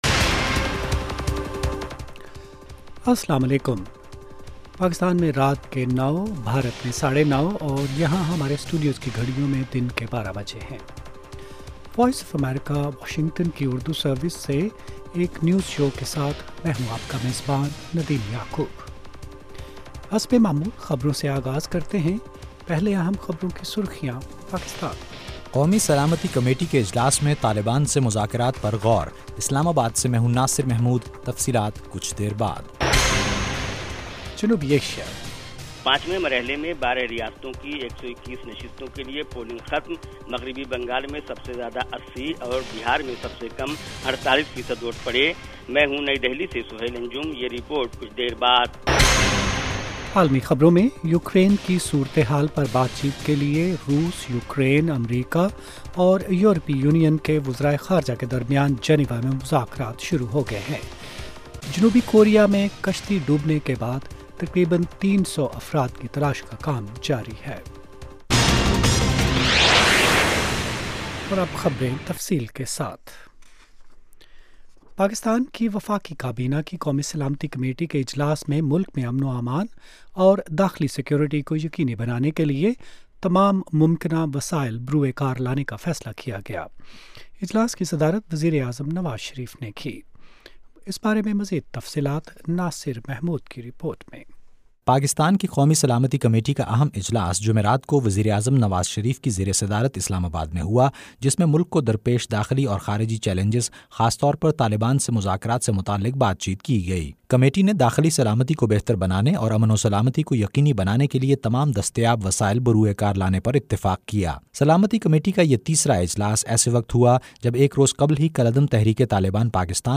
9:00PM اردو نیوز شو
پاکستان اور بھارت سے ہمارے نمائندوں کی رپورٹیں۔ اس کے علاوہ انٹرویو، صحت، ادب و فن، کھیل، سائنس اور ٹیکنالوجی اور دوسرے موضوعات کا احاطہ۔